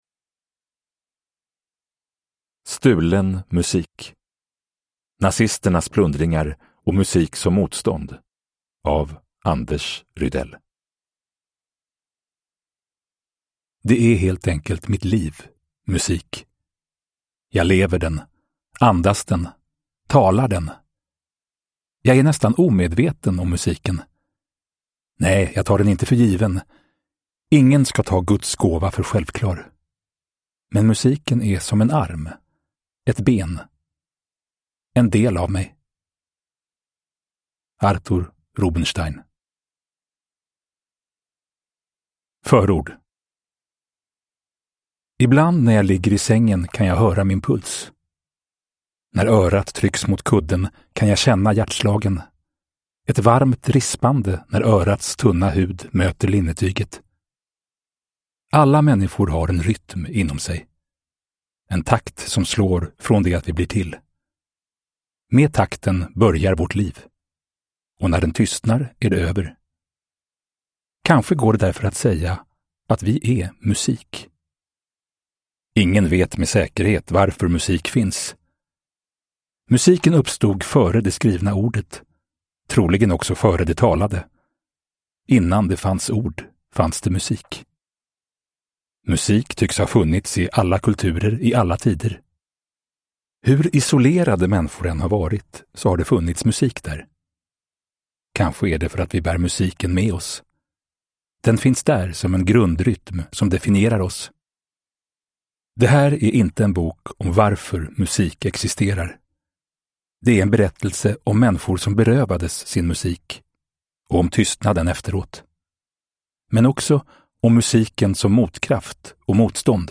Stulen musik : nazisternas plundringar och musik som motstånd – Ljudbok